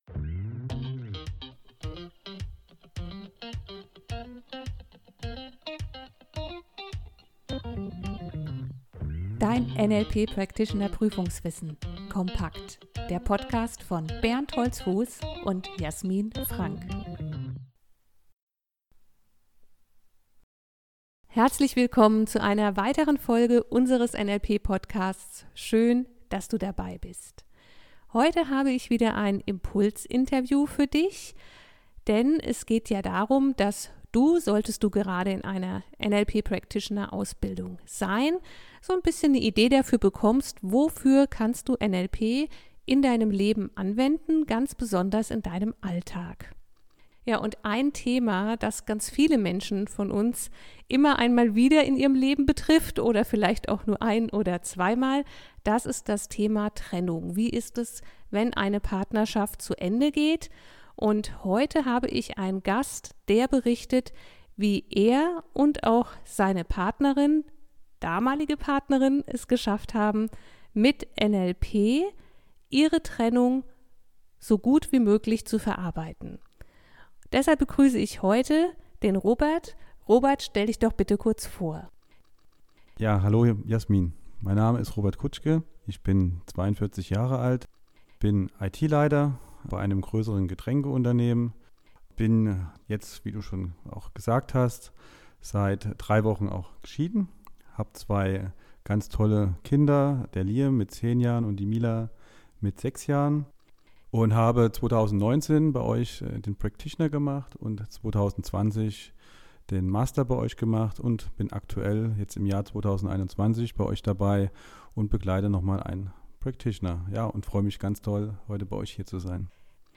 NLP Interview